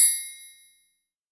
Triangle1.wav